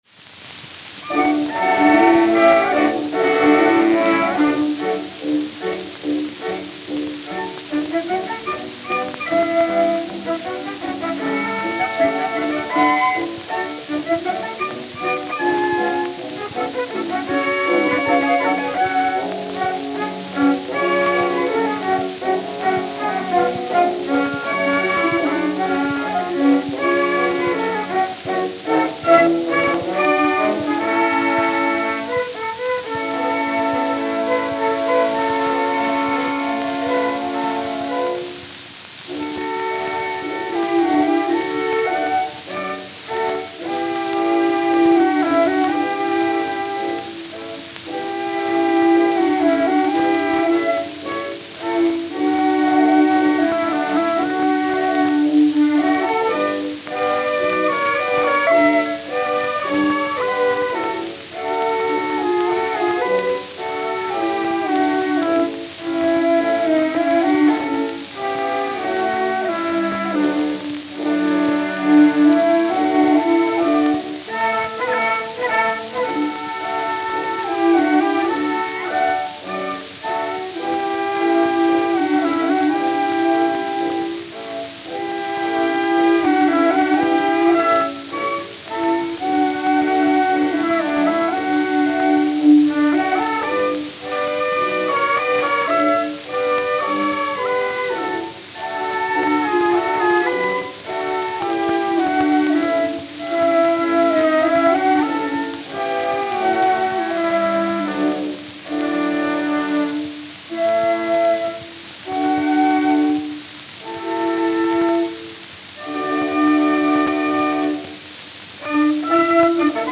Camden, New Jersey (?)
Note: Worn.